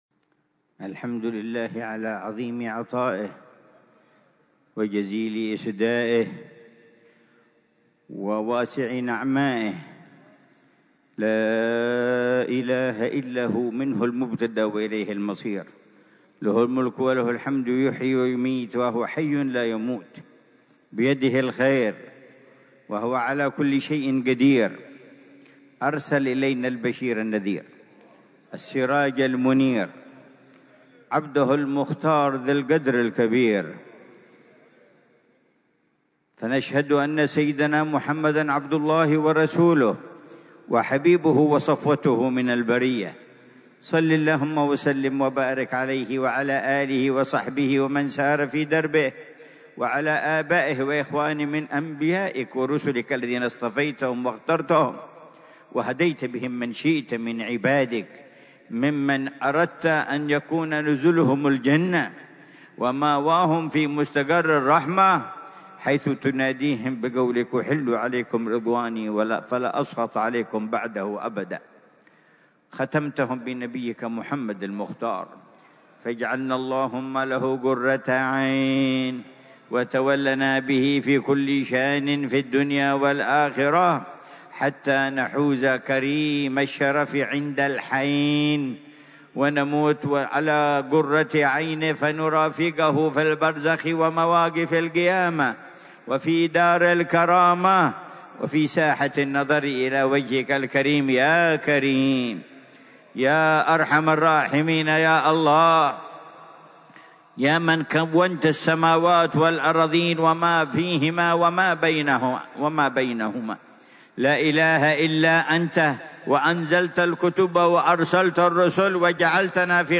محاضرة العلامة الحبيب عمر بن محمد بن حفيظ ضمن سلسلة إرشادات السلوك، ليلة الجمعة 25 ذو القعدة 1446هـ في دار المصطفى بتريم، بعنوان: